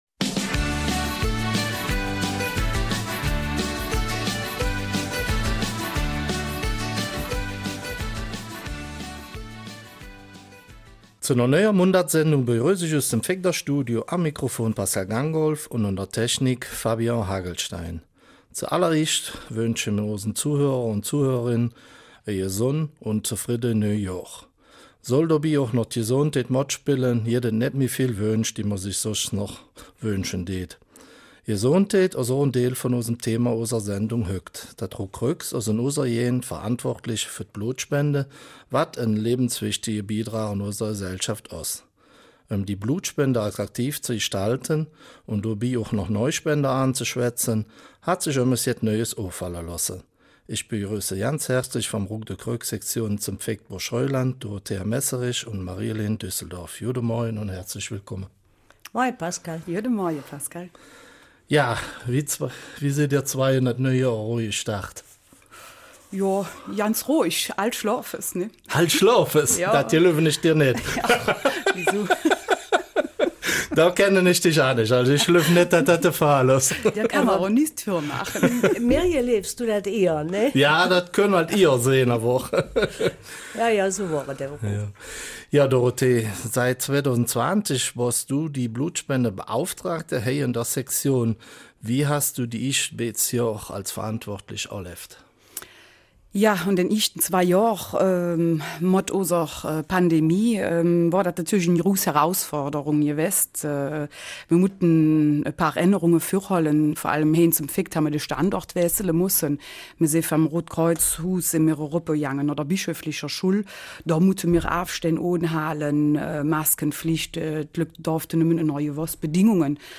Eifeler Mundart: Blutspende-Aktion